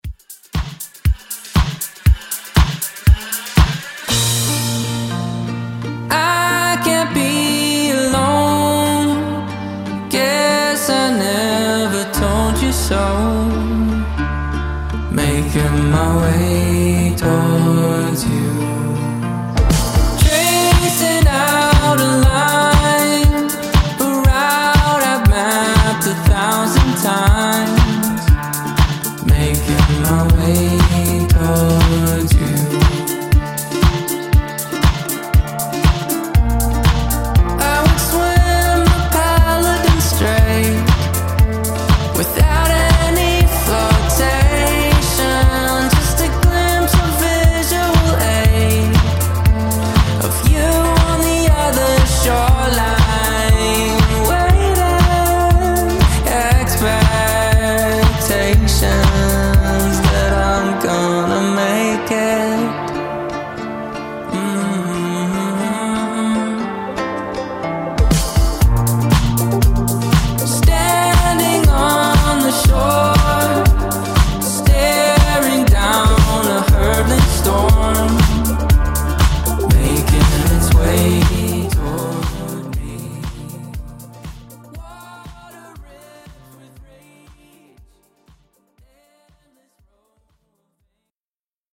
Genre: GERMAN MUSIC
Clean BPM: 86 Time